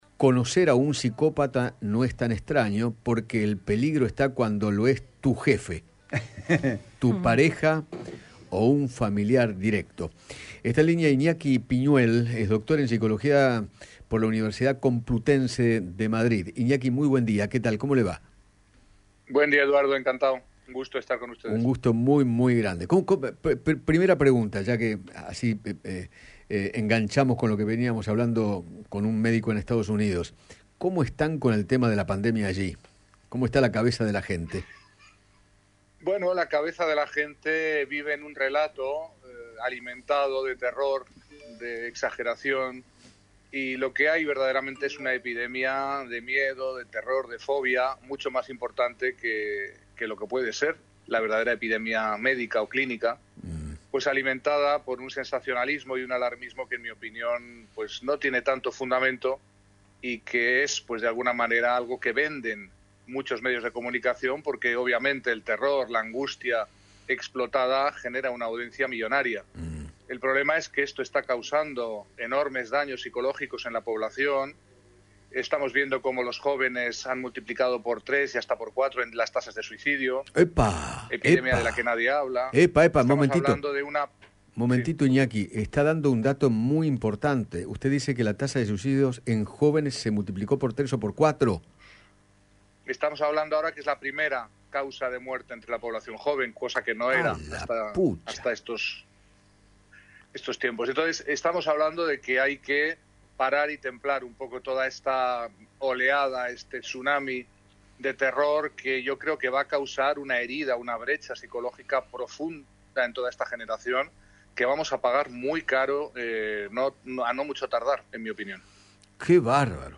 dialogó con Eduardo Feinmann acerca de la depresión en los jóvenes